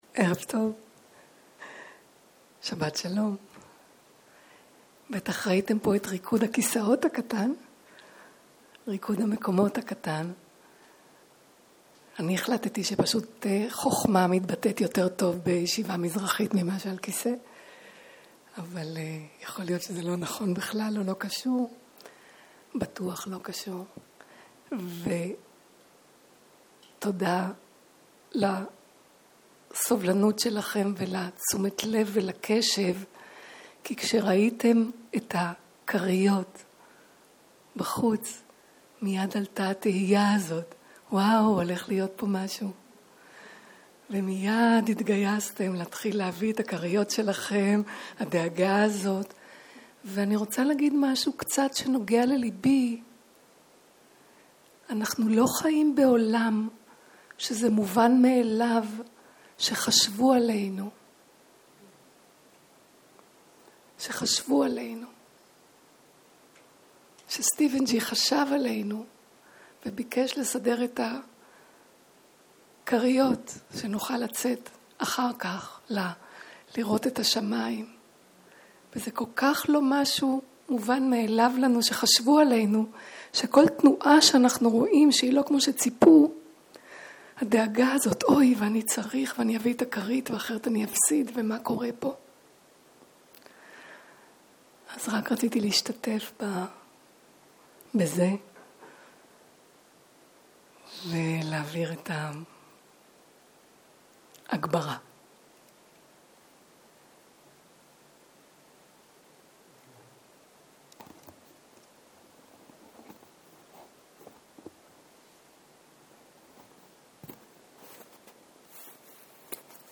סוג ההקלטה: שאלות ותשובות